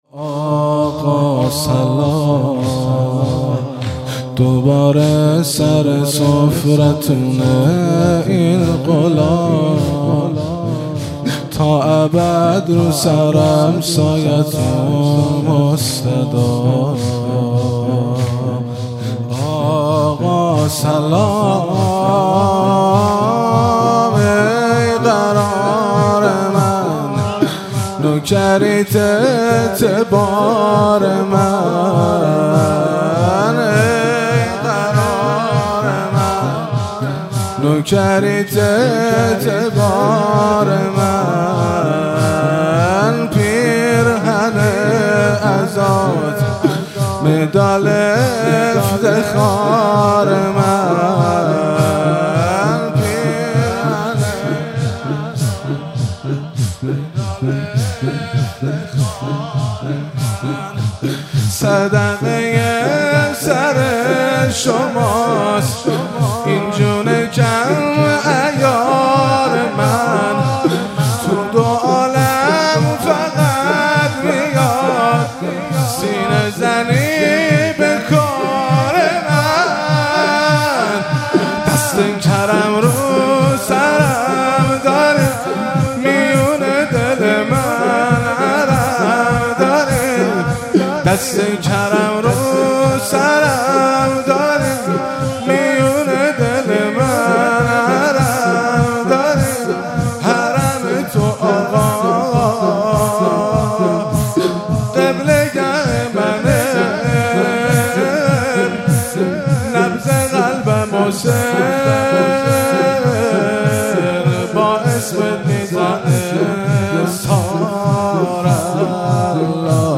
مراسم مناجات خوانی شب هفتم ماه رمضان 1444
سینه زنی- آقا سلام دوباره سر سفرتونه این غلام